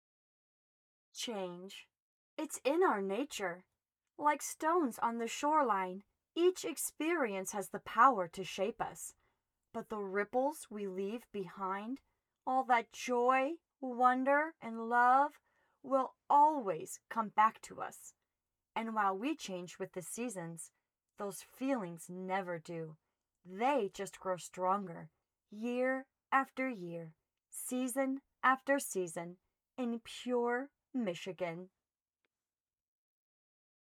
Professional-grade recording equipment and acoustically treated space